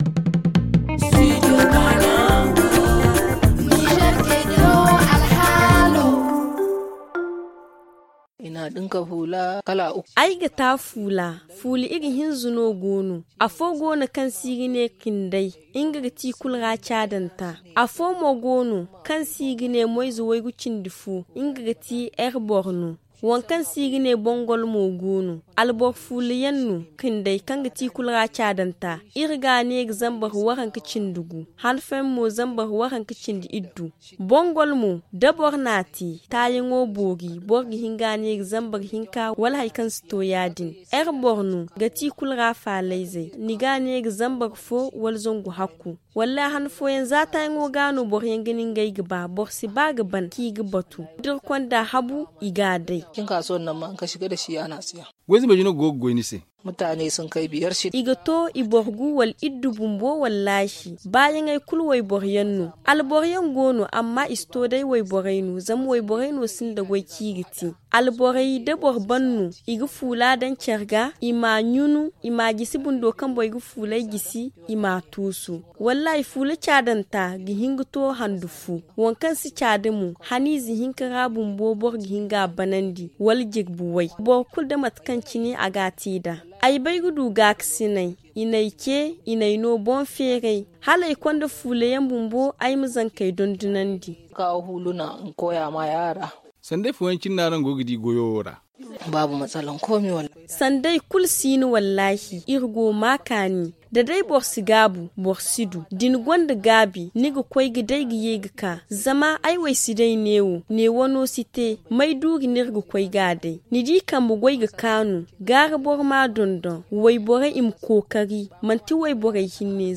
Notre correspondant a rencontré l’une d’elle qui exerce cette profession.